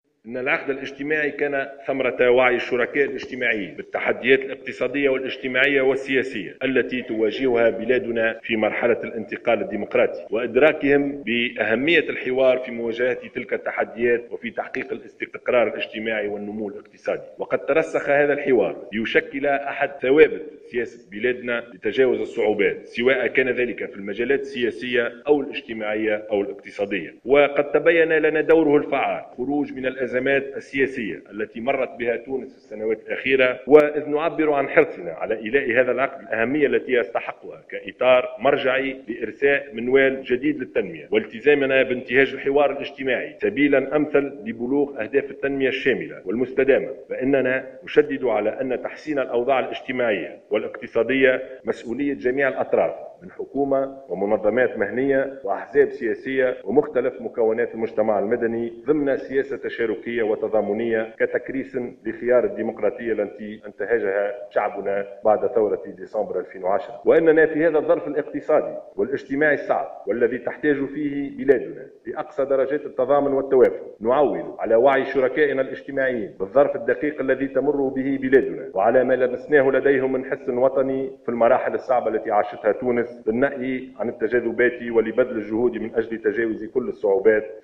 وأكد الشاهد، خلال افتتاح الندوة الثلاثية لاعتماد خطة عمل لتنفيذ العقد الاجتماعي، حرص الحكومة على إيلاء هذا العقد الأهمية التي يستحقها كإطار مرجعي لإرساء منوال جديد للتنمية، والتزامها باحترام مضامينه، وببذل كل الجهود من أجل تنفيذها".